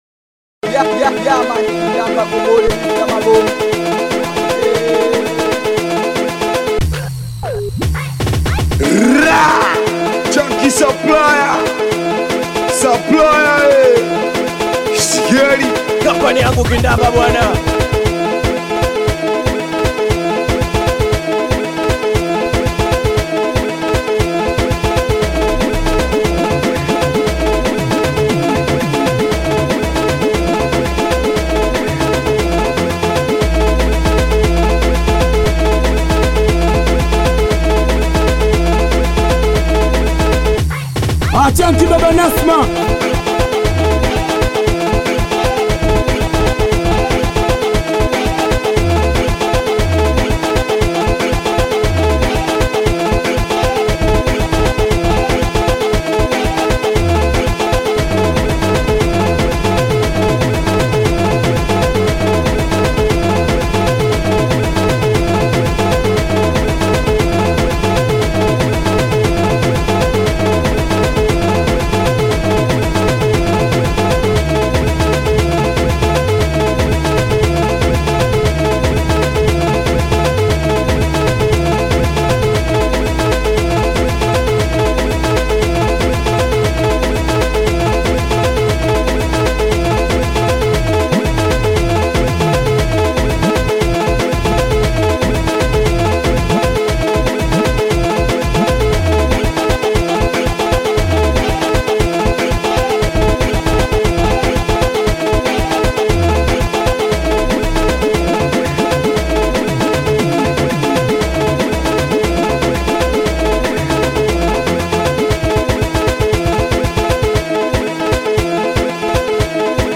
BITI SINGELI • SINGELI BEAT